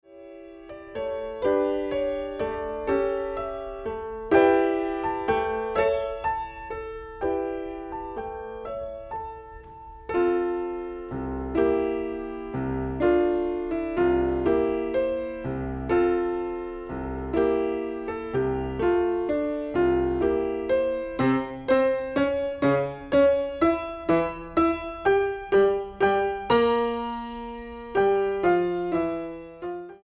Piano/Organ Ensembles Piano Duets
Piano Duet